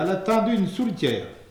Patois - ambiance
Catégorie Locution